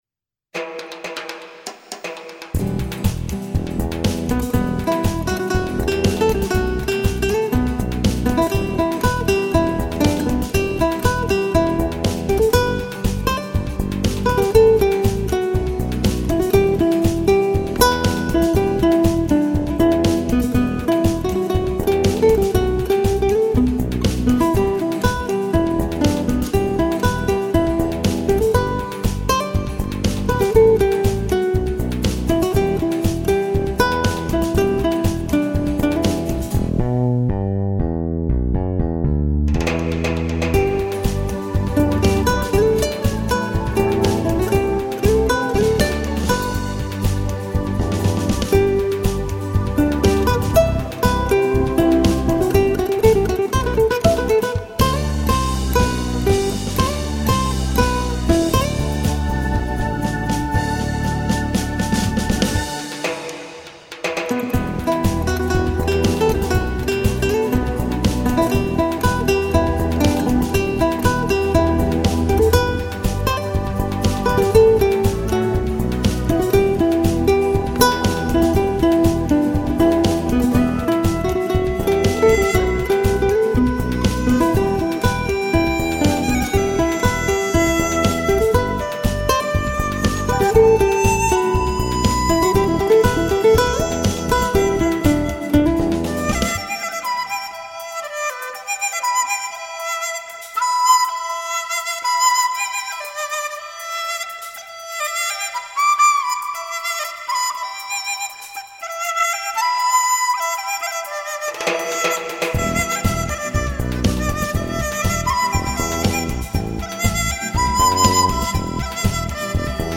江河